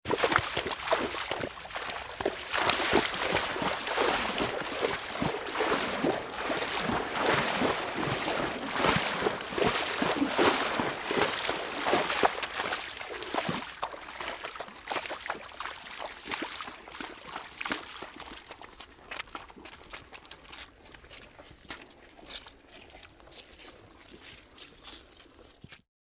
Погрузитесь в мир природы с подборкой звуков копыт лошадей: от размеренного шага до стремительного галопа.
Шаги лошади по воде звук